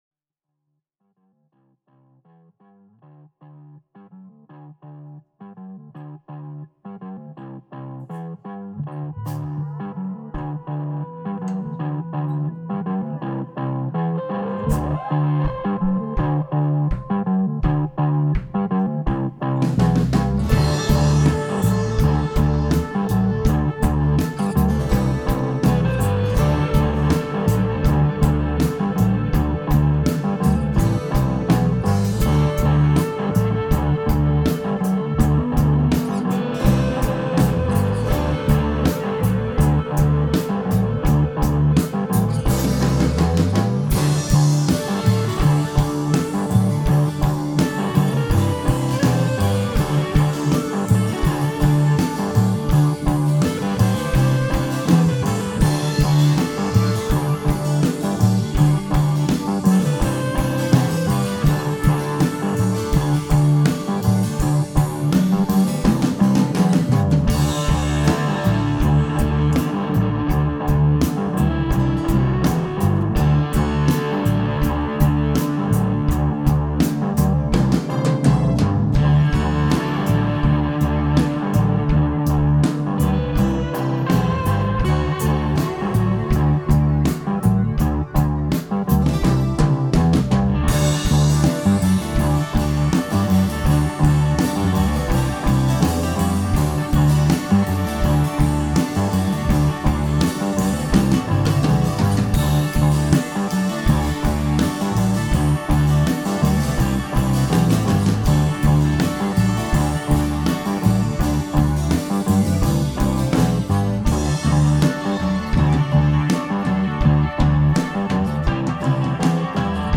Very acidic.